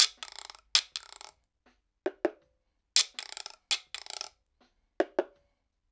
81-PERC2.wav